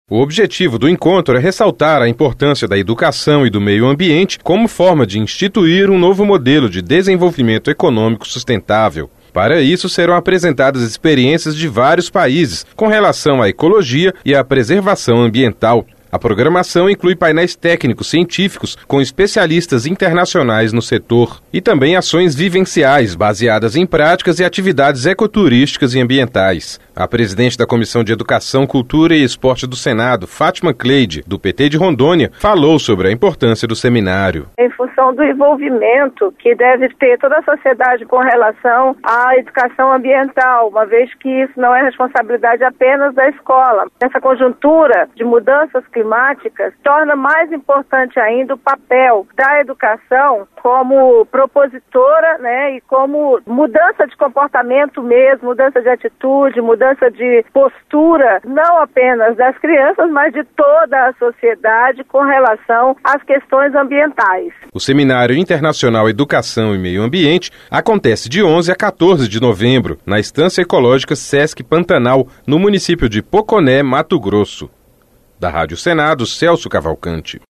A presidente da Comissão de Educação, Cultura e Esporte do Senado, Fátima Cleide, do PT de Rondônia, falou sobre a importância do seminário.